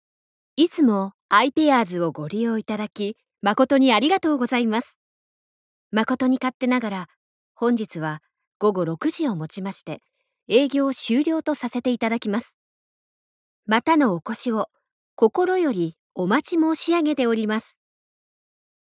演技版